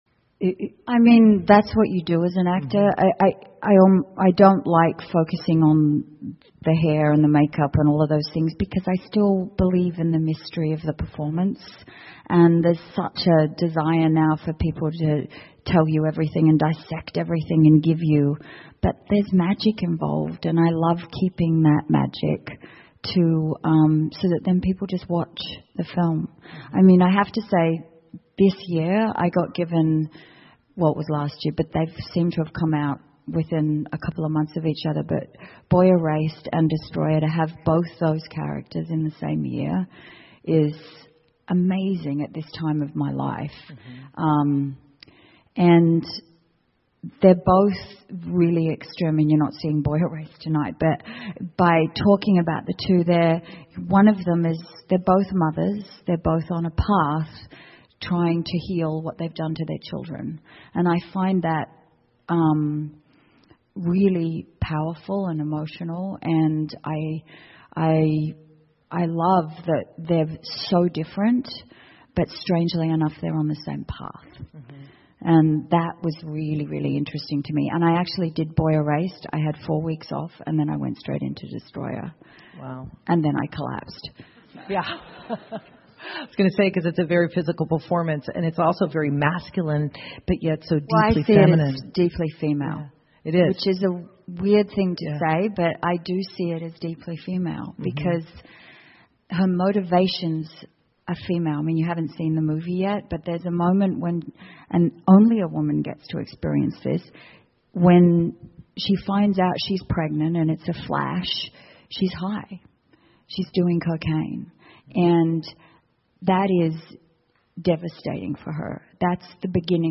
英文演讲录 妮可·基德曼：我的演艺生涯(3) 听力文件下载—在线英语听力室